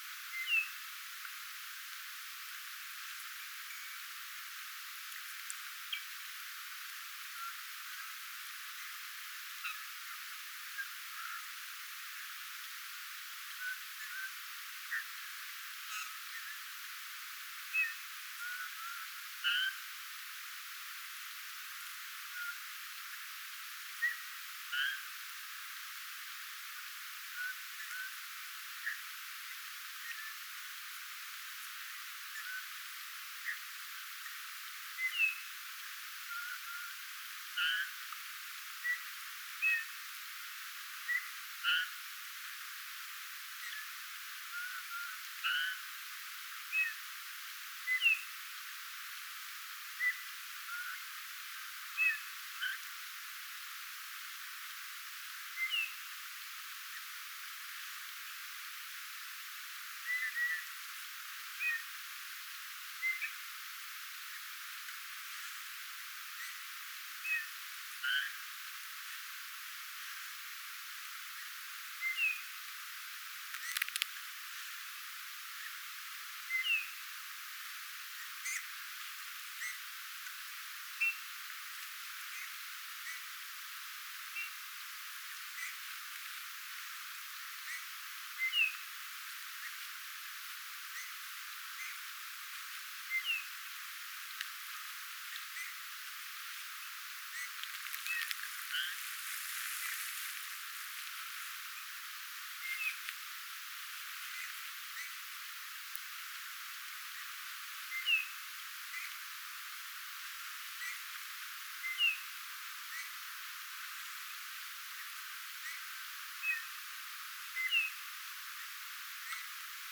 Ja punatulkku lauloi luontopolun varrella.
Lauluu kuului erikoisia "näppäilyjä"
ja esimerkiksi tulii-ääniä.
tulii laulava punatulkku
tulii-laulava_punatulkku_tulii-aani_osa_laulua.mp3